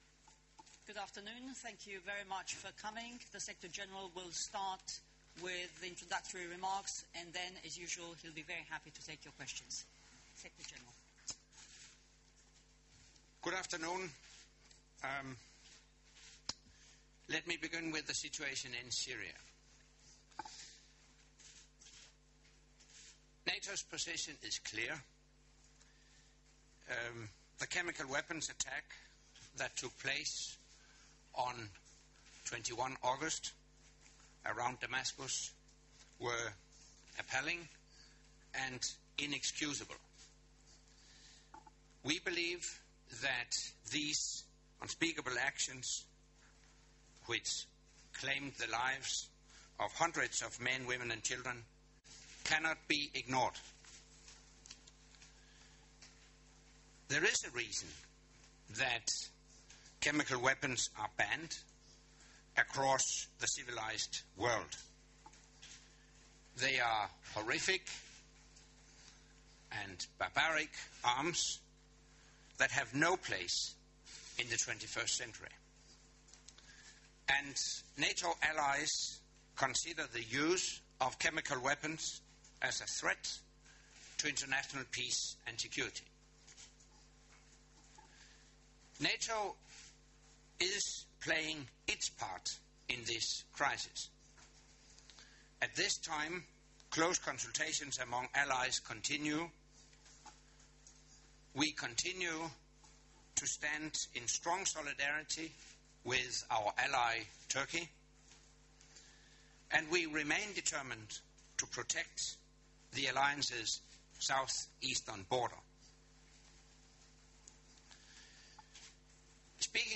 Secretary General's Monthly Press Conference
On 2 September NATO Secretary General Anders Fogh Rasmussen held his monthly press conference at the Residence Palace, Brussels.